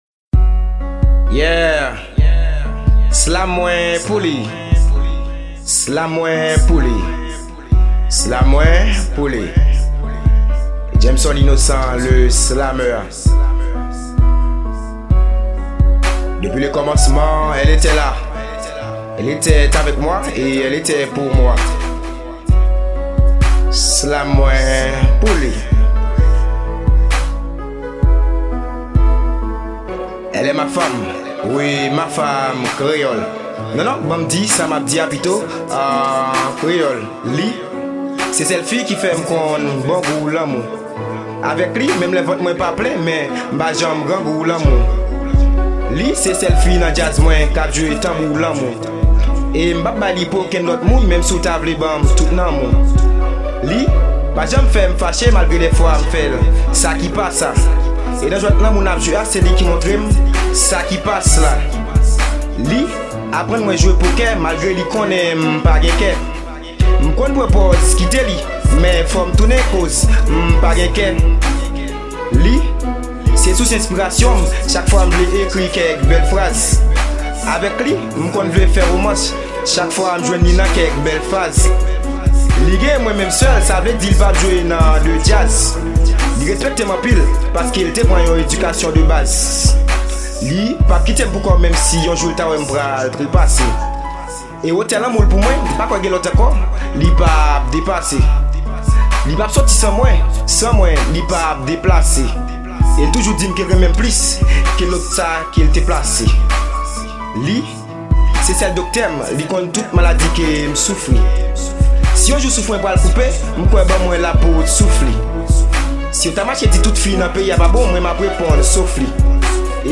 slam
Genre : RAP